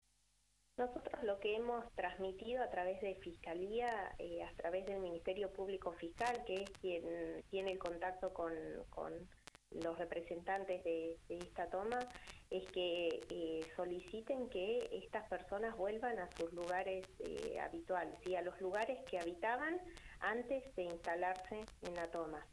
Neuquén.- En diálogo con «Mañanas en Red» por LA RED NEUQUÉN (93.7), la ministra de Gobierno, Vanina Merlo, dijo que las familias de la toma de Casimiro Gómez no recibirán asistencia ante la pandemia que golpea al mundo.